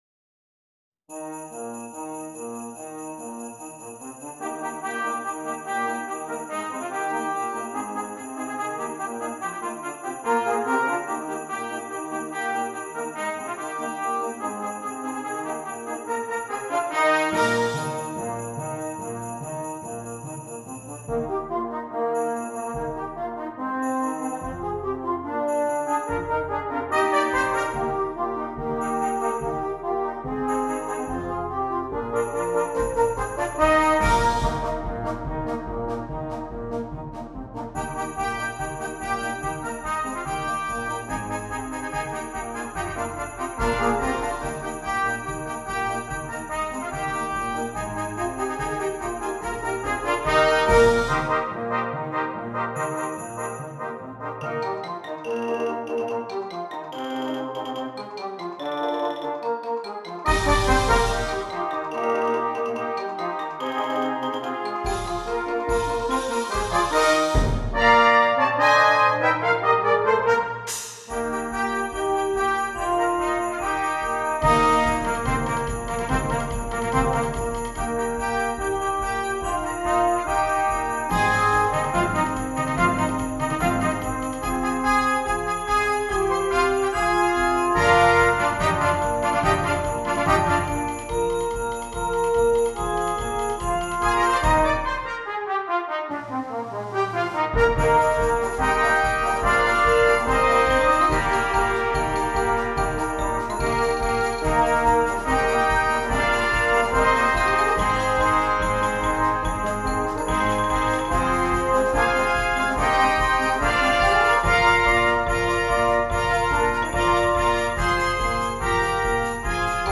10 Trumpets and Percussion
Traditional Carol
Fast paced and furious
high energy piece